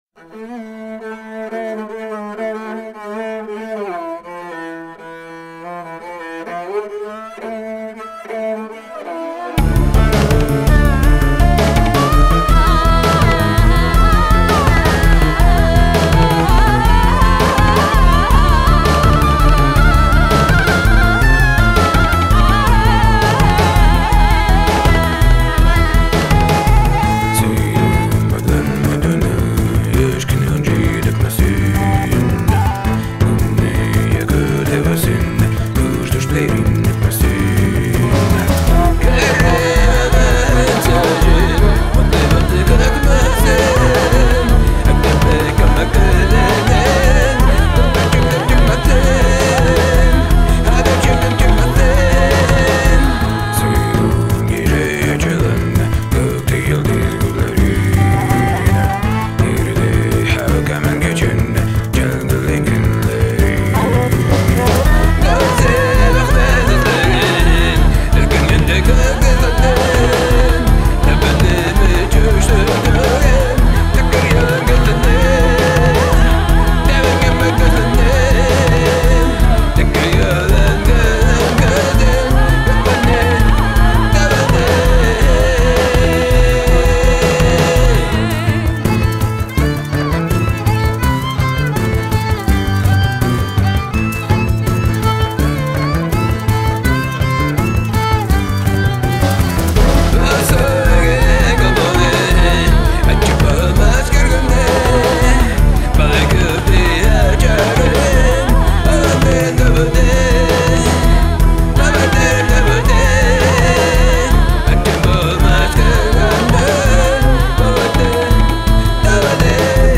groupe "ethno-rock sibérien"